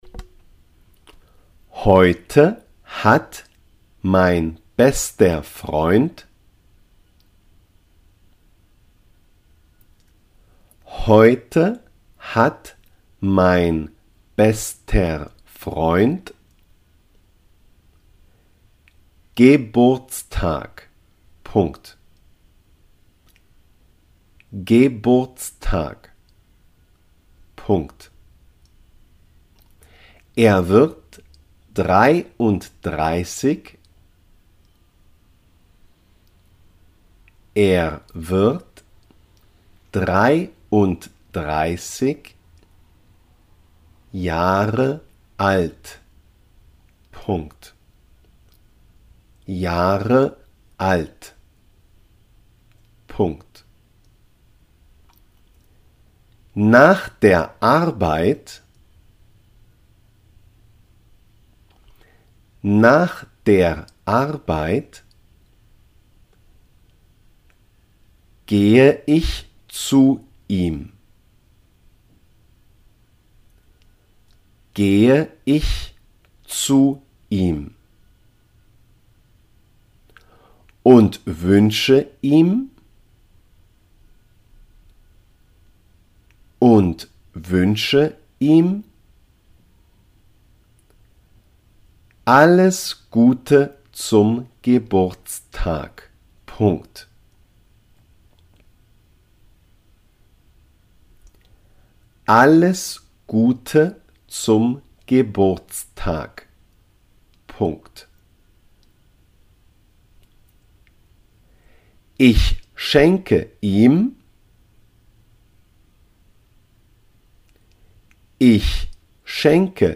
el-cumpleanos-dictado-en-aleman-AprendeAleman.com_-1.mp3